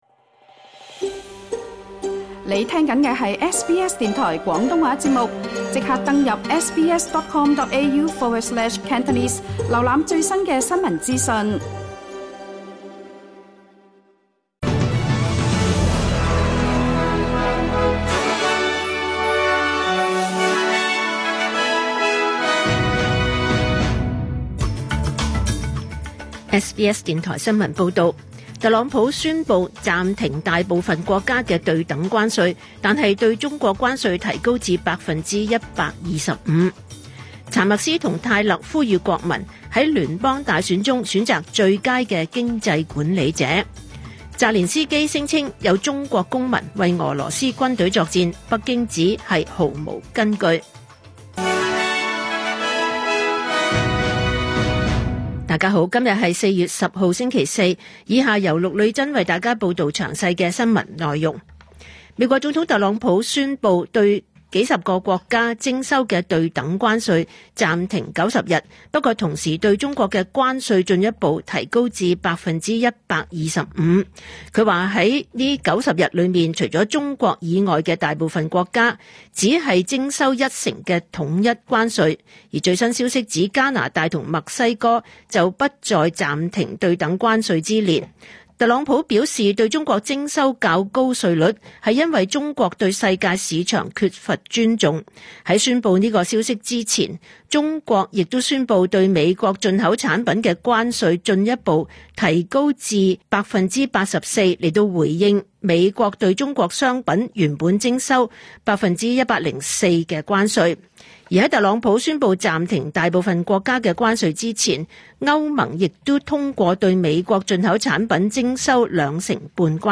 2025 年 4 月 10 日 SBS 廣東話節目詳盡早晨新聞報道。